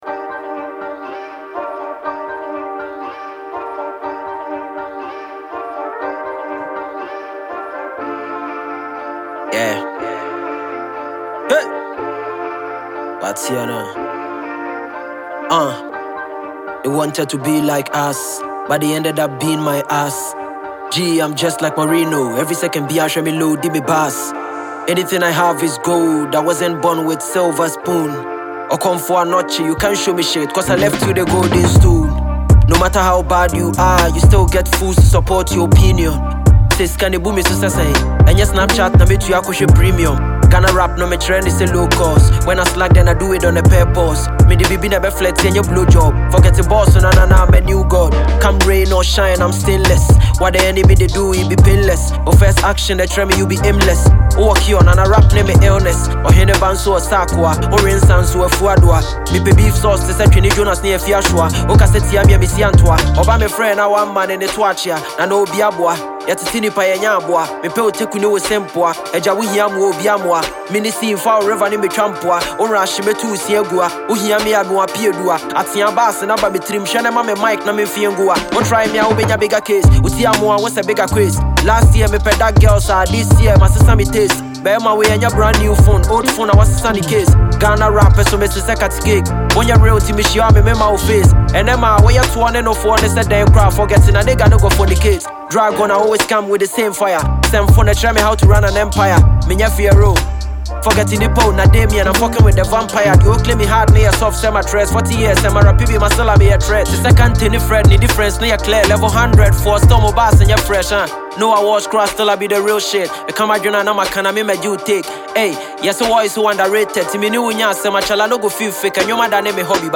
thought-provoking rap song